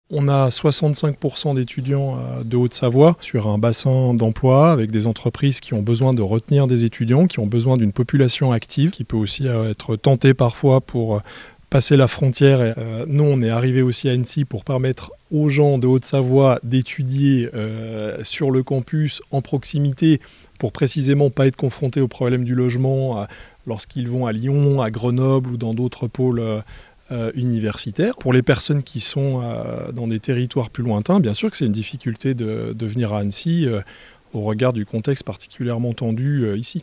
Il en parle au micro d'ODS radio.